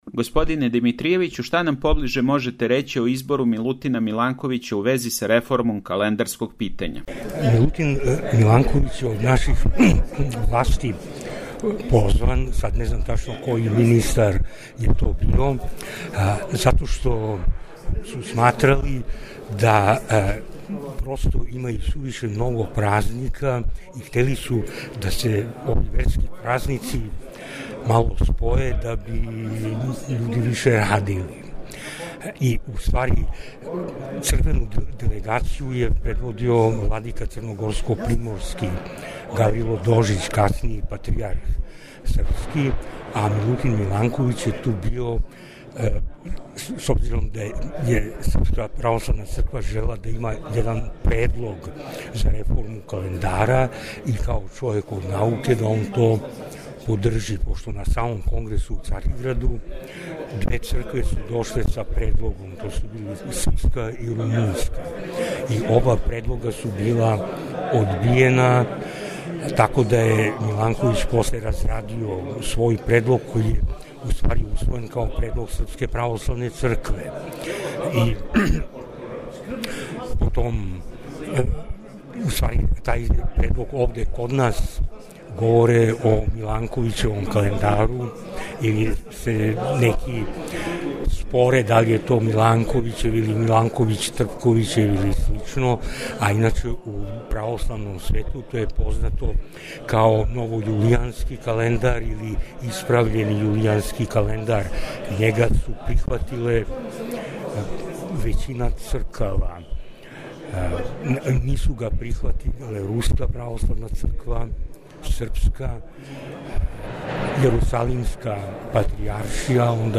Изјава